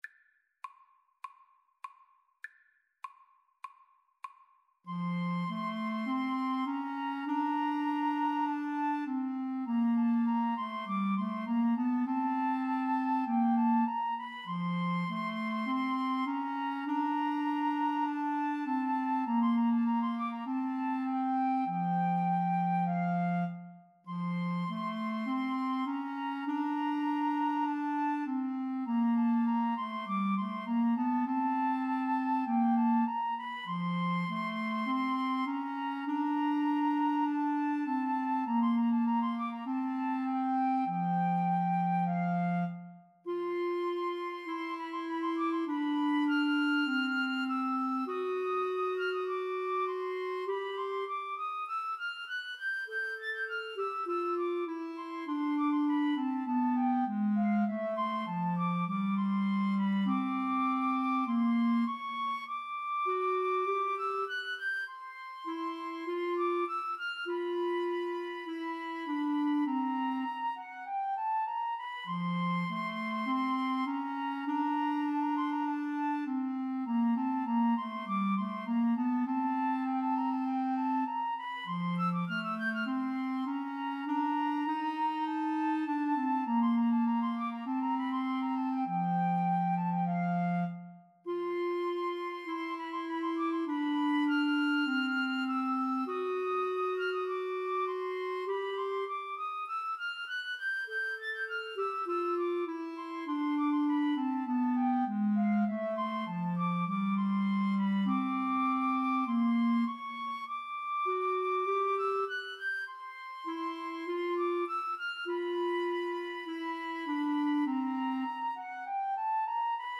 Flute 1Flute 2Clarinet
4/4 (View more 4/4 Music)
Classical (View more Classical 2-Flutes-Clarinet Music)